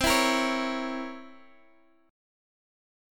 C7b9 chord